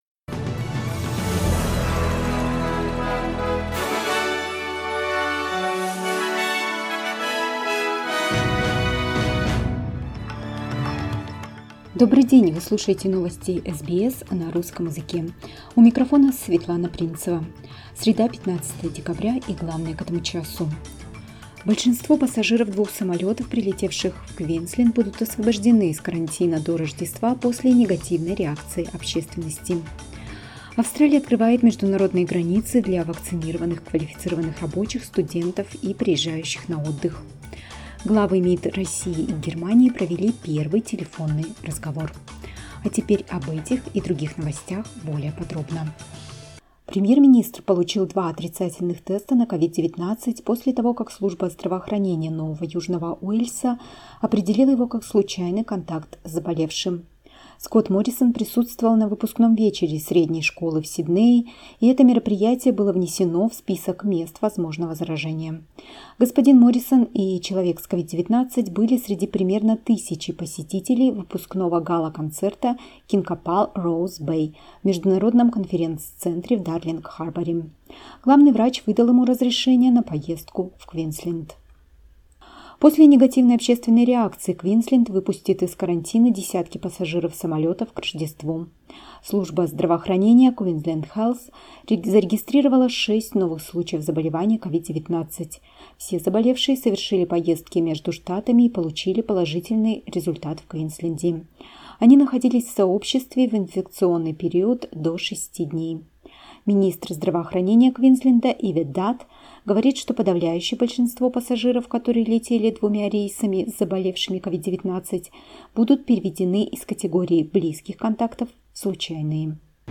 Listen to the top news from SBS Russian.